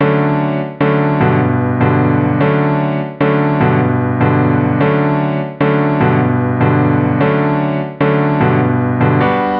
旧流Rnb钢琴
描述：我的爵士乐像流动的钢琴
Tag: 100 bpm RnB Loops Piano Loops 1.62 MB wav Key : Unknown